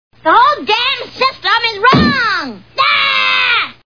The Simpsons [Lisa] Cartoon TV Show Sound Bites